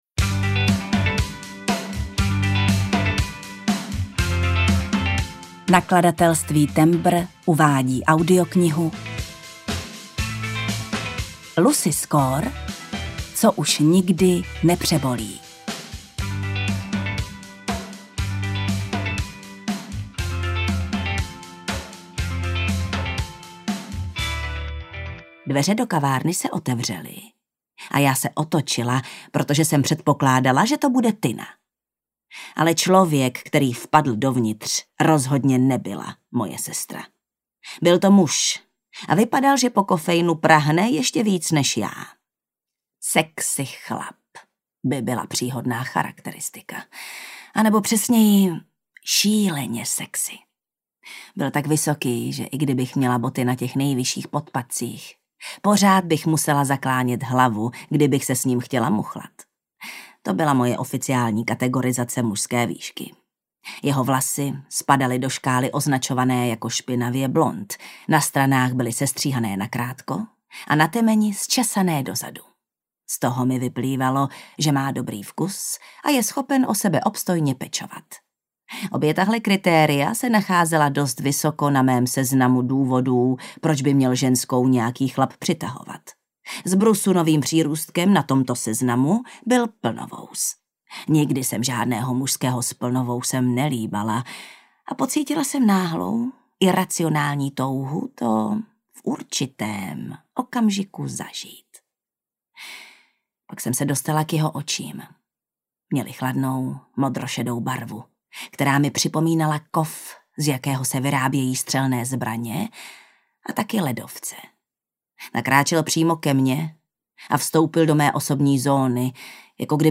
Ukázka z knihy
• InterpretJana Stryková, Vasil Fridrich